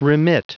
Prononciation du mot remit en anglais (fichier audio)
Prononciation du mot : remit